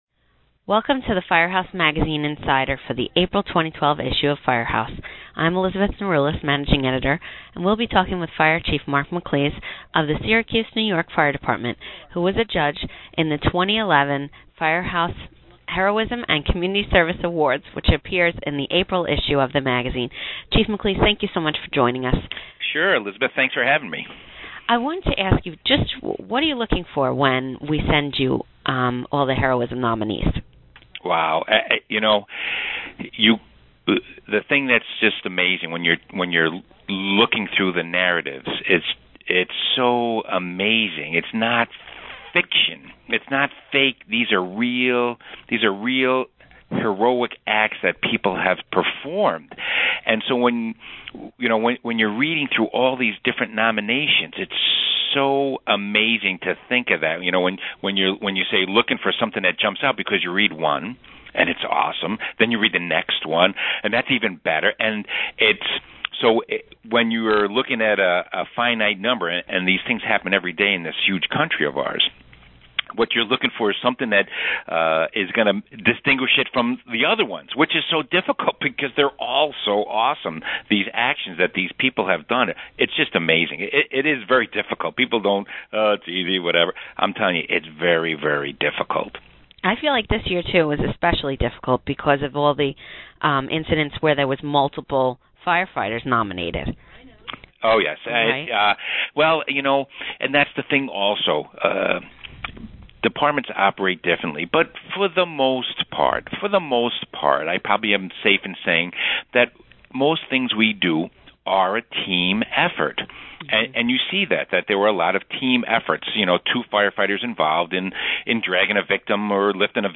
Listen as the two discuss this year's awards program and some of the difficulties that came with judging such important heroic acts.